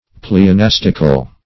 Search Result for " pleonastical" : The Collaborative International Dictionary of English v.0.48: Pleonastic \Ple`o*nas"tic\, Pleonastical \Ple`o*nas"tic*al\, a. [Cf. F. pl['e]onastique.]